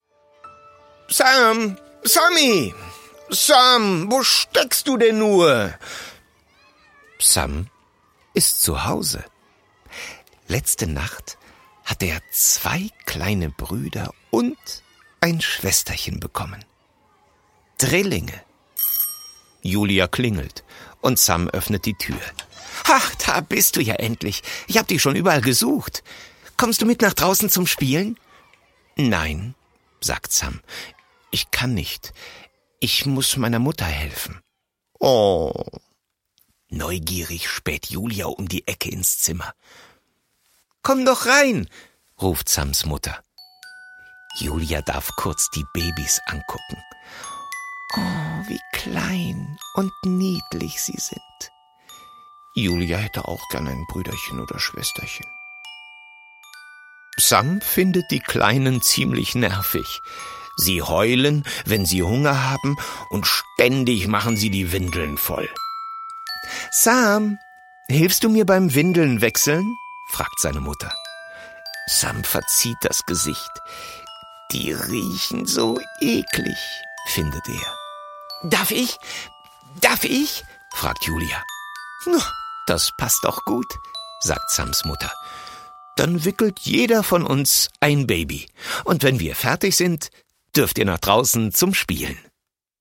Schlagworte Hörbuch; Lesung für Kinder/Jugendliche • Mäuse • Puppenhaus • Theater